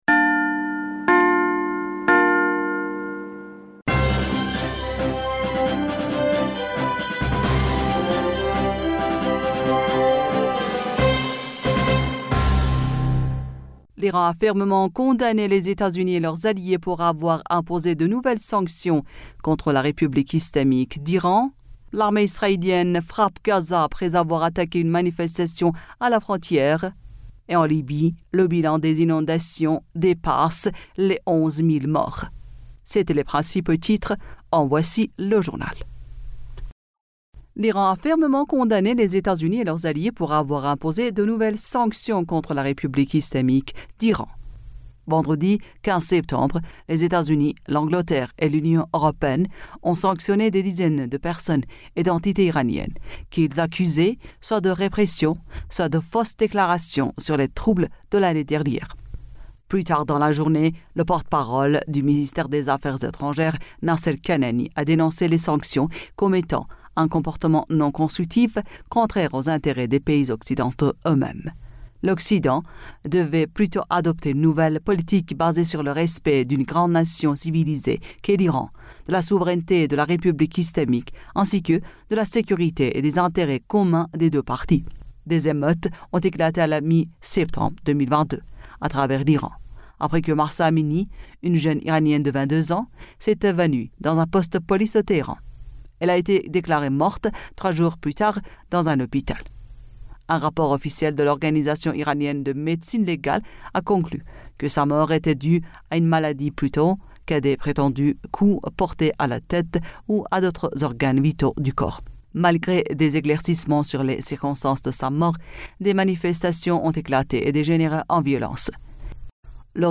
Bulletin d'information du 16 Septembre 2023